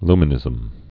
(lmə-nĭzəm)